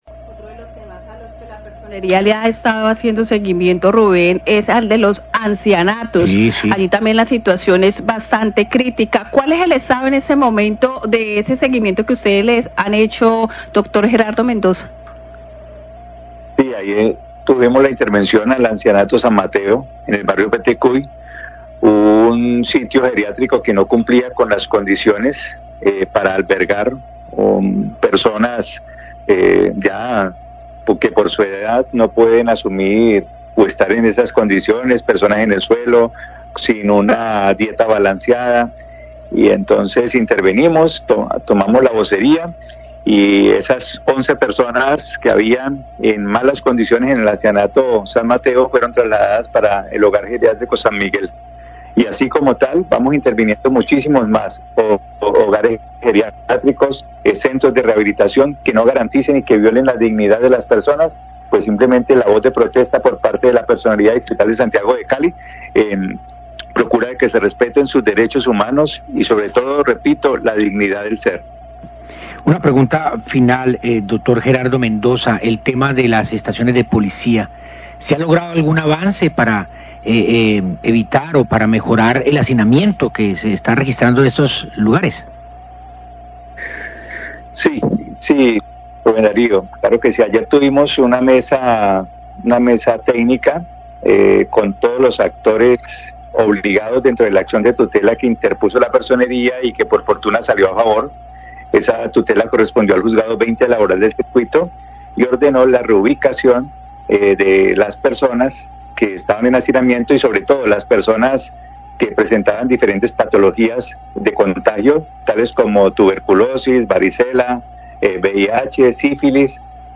Radio
Fue preguntado por el avance para acabar con el hacinamiento de las personas detenidas en las estaciones de Policía.